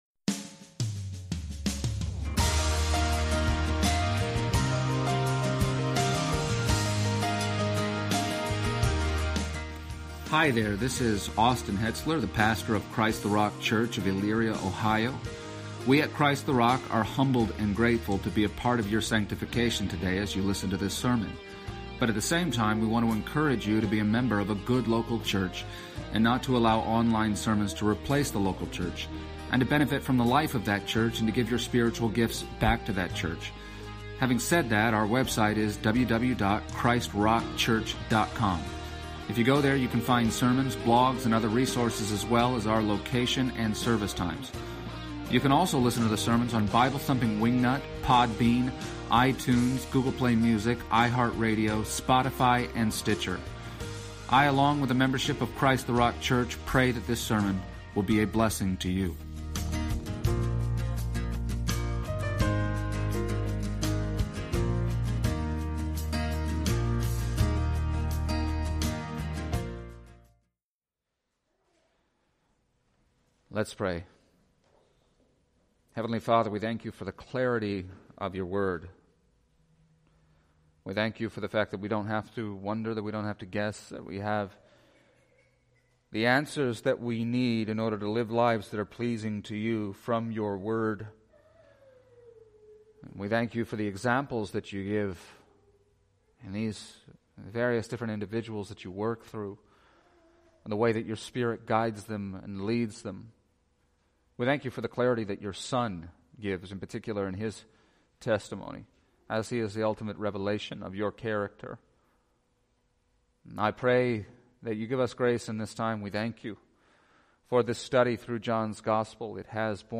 Passage: John 21:15-25 Service Type: Sunday Morning